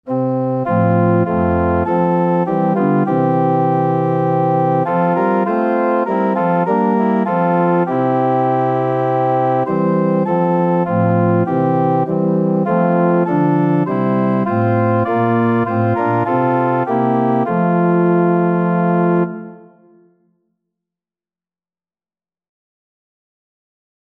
Free Sheet music for Organ
4/4 (View more 4/4 Music)
F major (Sounding Pitch) (View more F major Music for Organ )
Organ  (View more Easy Organ Music)
Classical (View more Classical Organ Music)